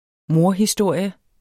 Udtale [ ˈmoɐ̯- ]